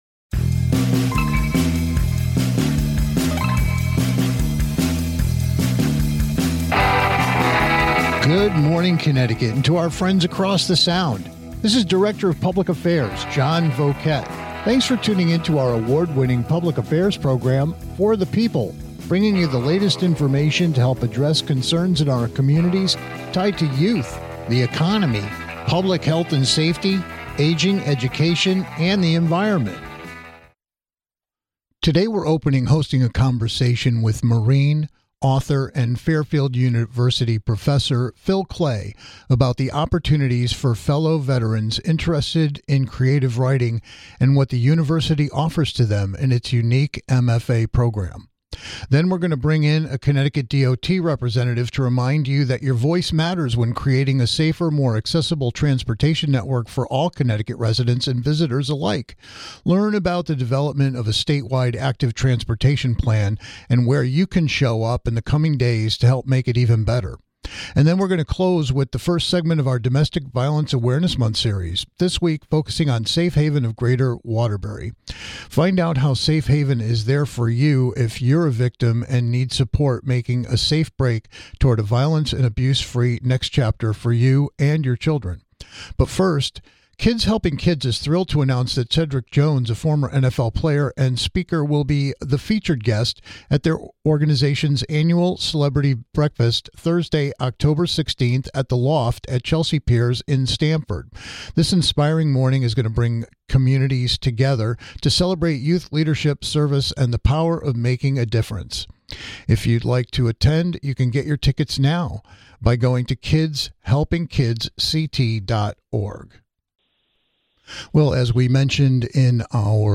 Today we're hosting a conversation with Marine, author and Fairfield University Professor Phil Klay about the opportunities for fellow veterans interested in creative writing - and what the university offers to those who served, or anyone interested in its unique MFA program.